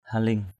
/ha-lɪŋ/ (d.) tên ao rộng ở Tánh Linh (Bình Tuy ) = nom d’une vaste étendue d’eau à Tánh Linh (Bình Tuy).